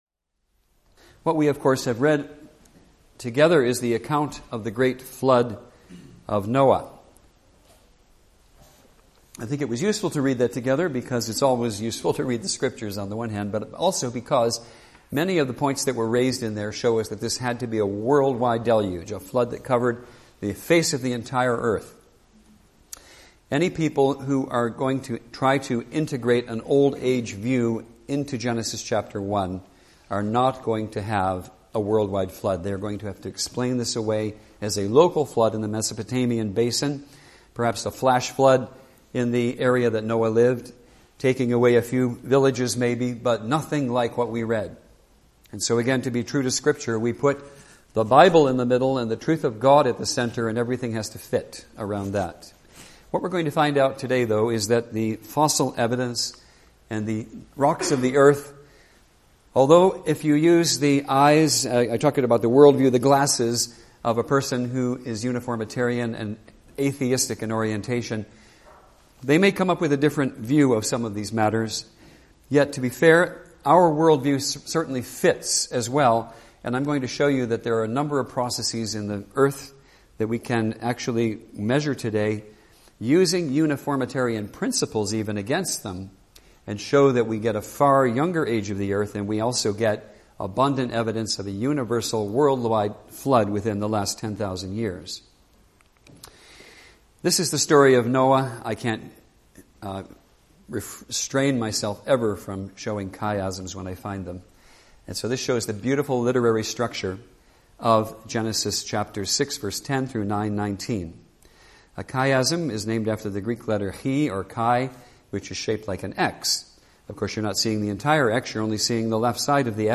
Apologetics Conference